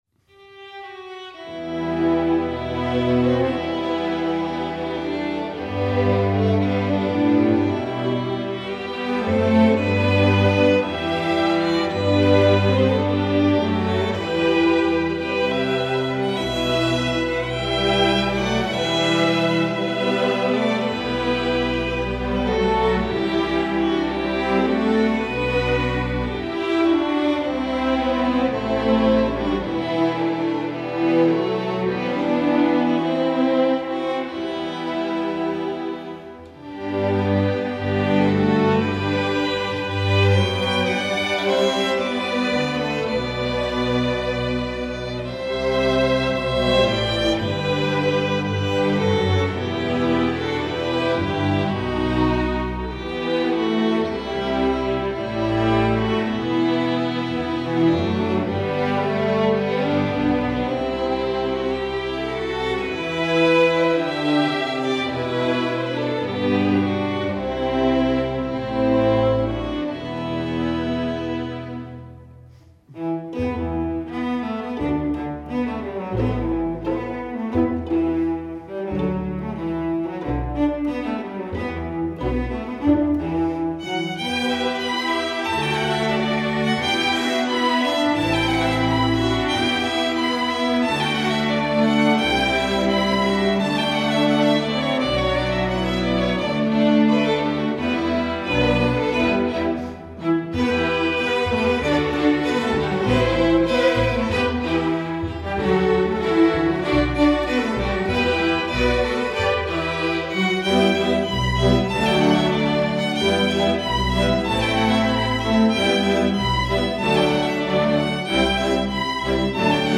Instrumentation: string orchestra (full score)